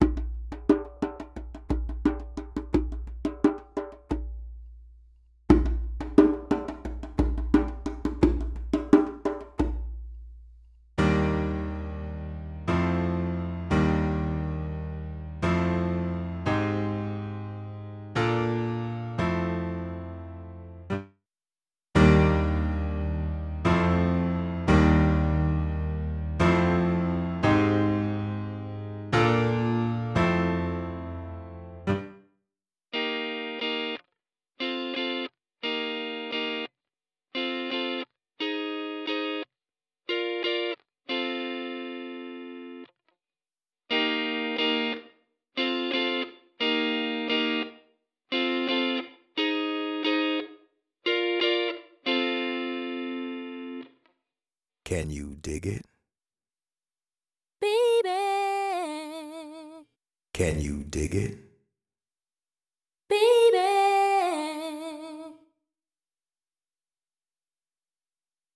Реверберация типа Room:
Но room-отражения более естественные: время затухания меньше, хвосты еле-заметные. Room-реверберация используется для создания легкого объема, который не воспринимался бы слушателем как особый спецэффект.
Room.mp3